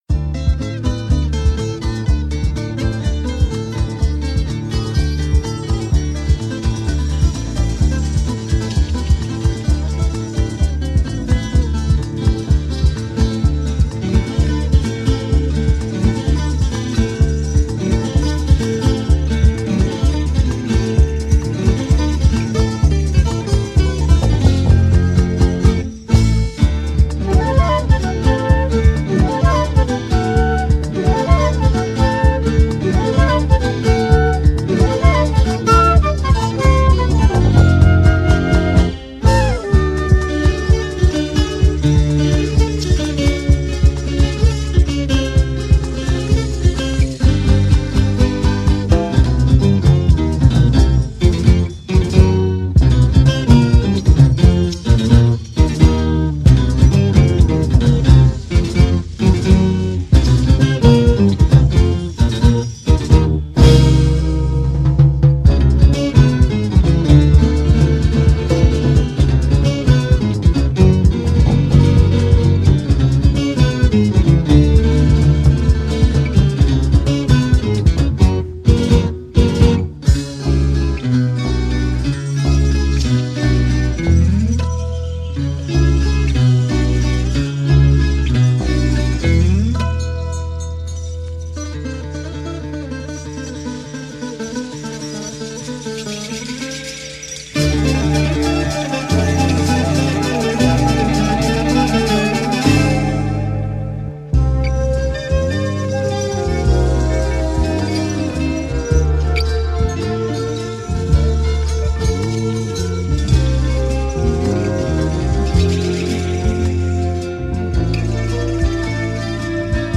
voz e violão
contrabaixo
viola e cavaquinho
flauta